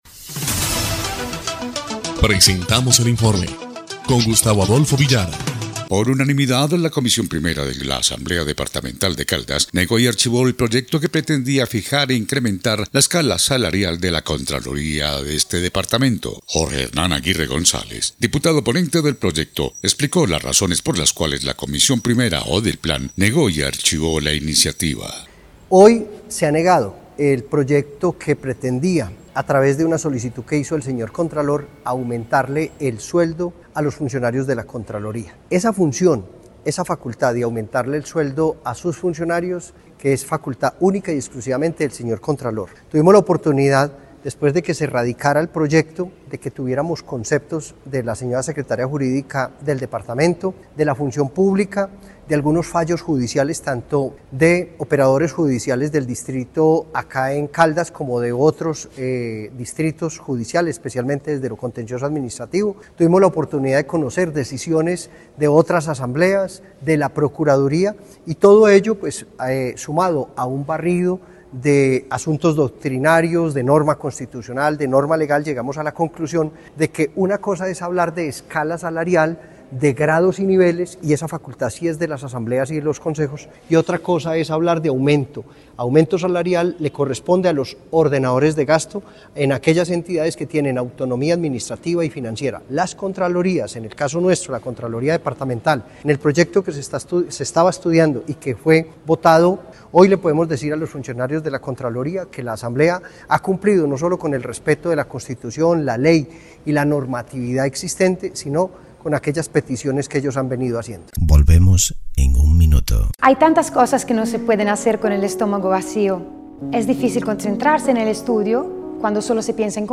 EL INFORME 1° Clip de Noticias del 30 de julio de 2025